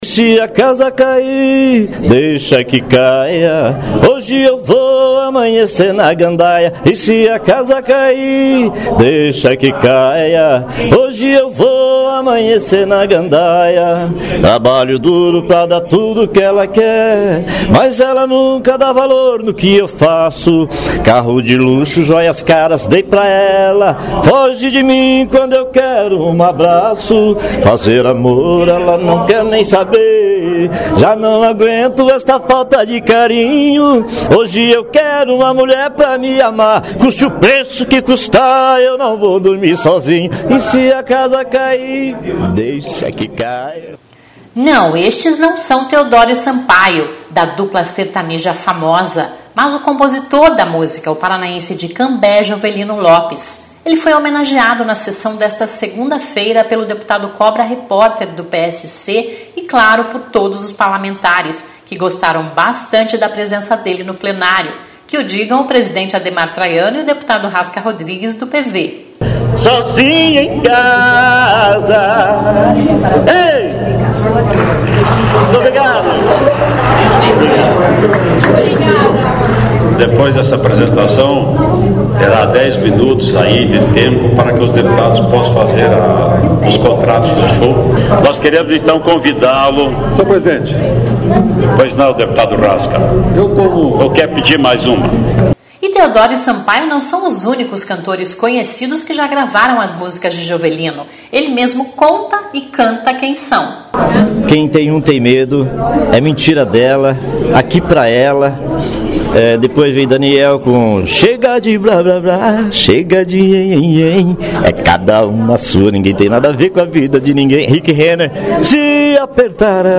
(Descrição do áudio)(começa com Sobe som)Não.